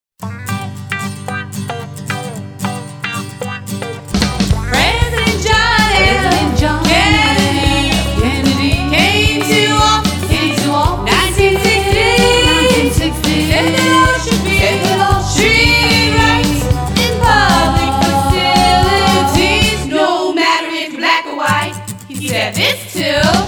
MP3 Demo Vocal Track